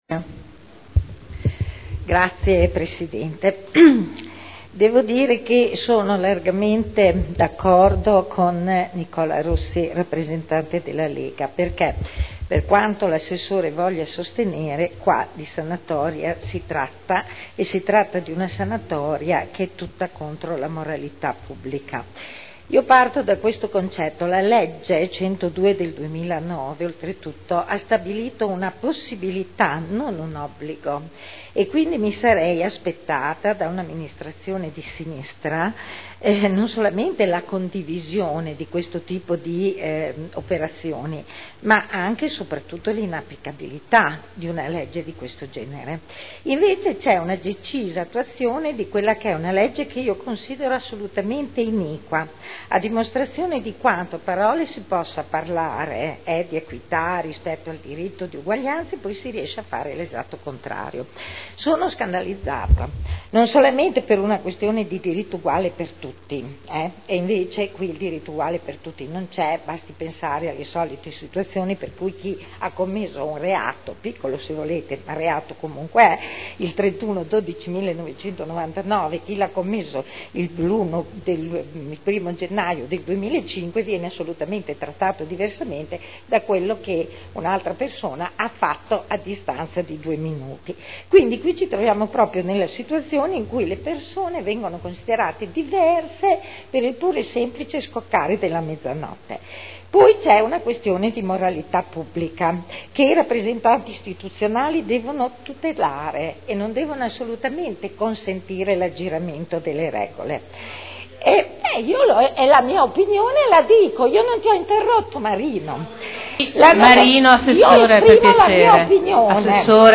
Eugenia Rossi — Sito Audio Consiglio Comunale
Seduta del 20/12/2010. Dibattito su delibera: Definizione agevolata dei debiti derivanti da sanzioni relative a verbali di accertamento di violazioni al codice della strada elevati dal 1.1.2000 al 31.12.2004 (art. 15 comma 8 quinquiesdecies legge 3.8.2009 n. 102) (Commissione consiliare del 14 dicembre 2010)